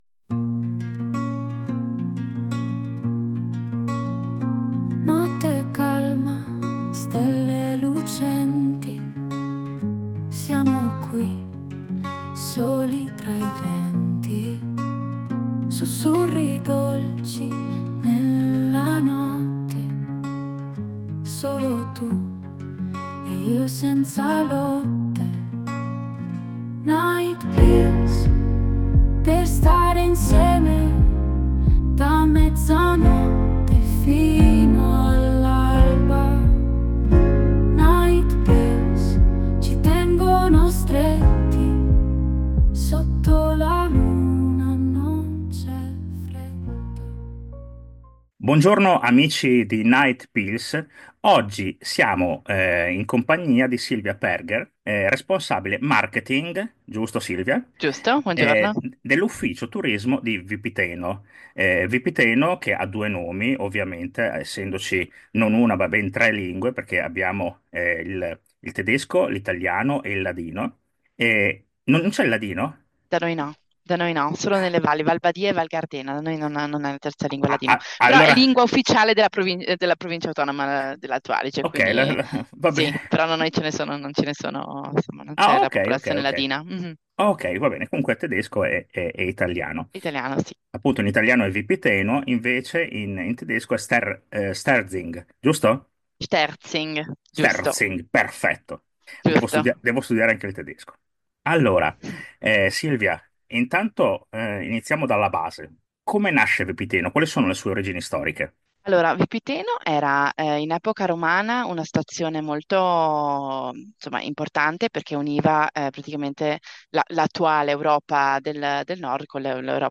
In questo articolo, oltre all’intervista potete vedere foto e spot video di questo magico borgo e, mi raccomando, non dimenticate di visitare i mercatini di Natale di Vipiteno-Sterzing fino al 6 gennaio 2025.